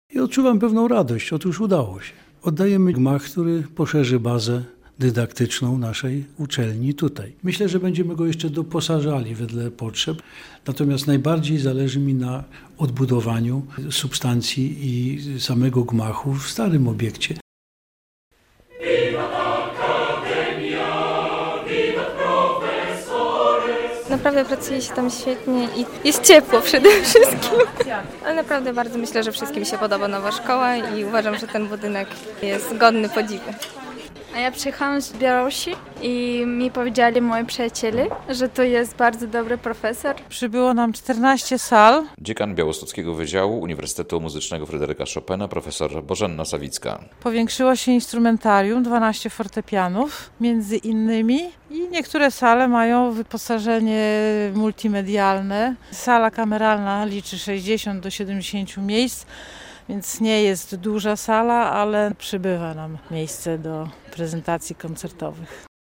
Inauguracja nowego roku akademickiego na Wydziale Instrumentalno-Pedagogicznym UMFC - relacja